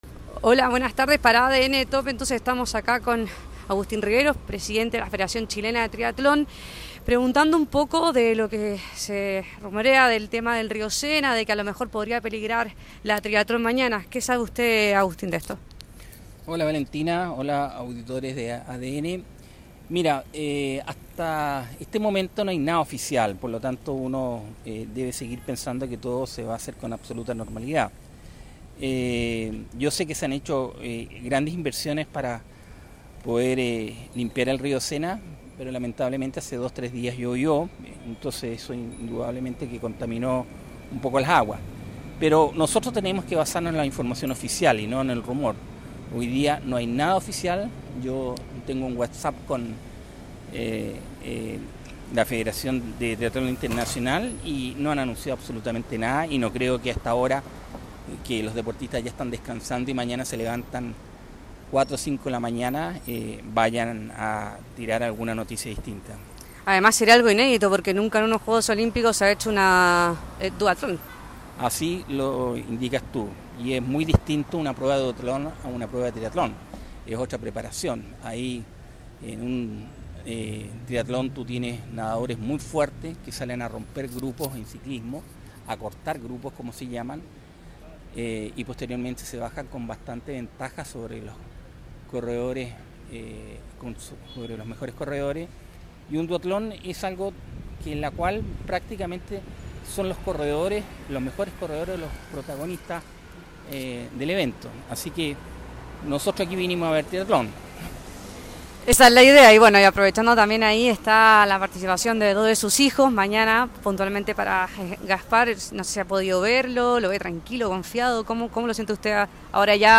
En conversación con ADN TOP KIA